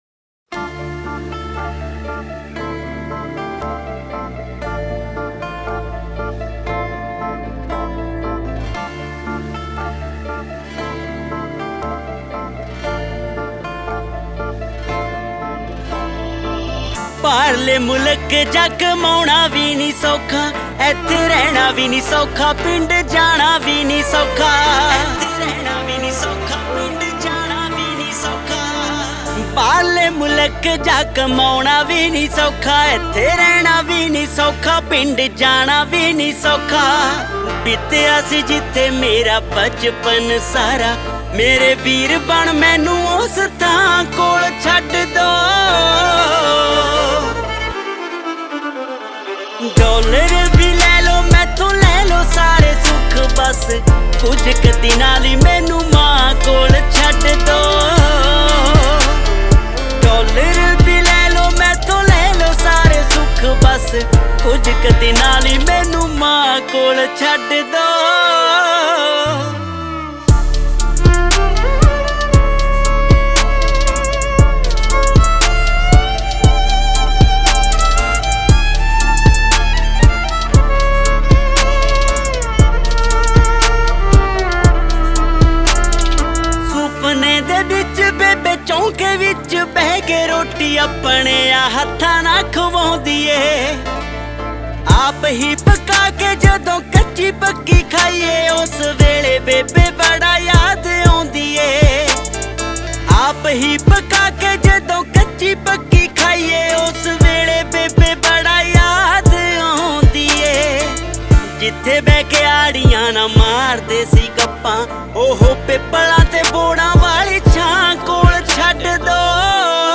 Song Genre : Latest Punjabi Songs